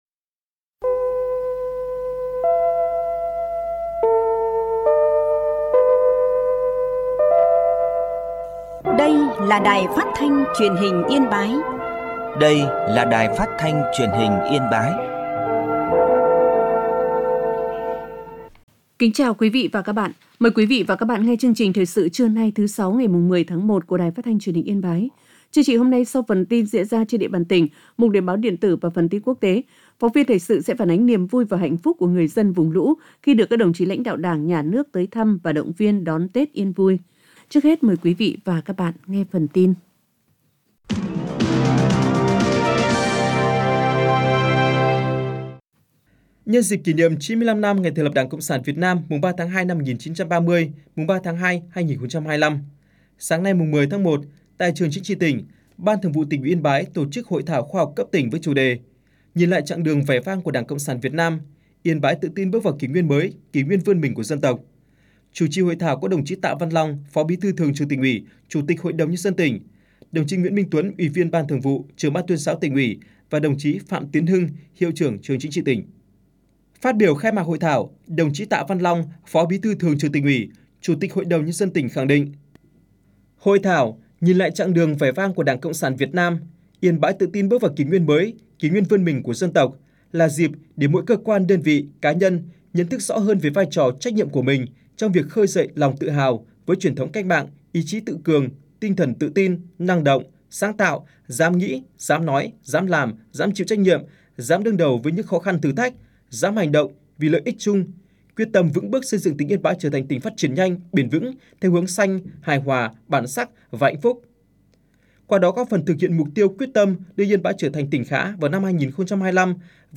Thoi_su_trua_10.mp3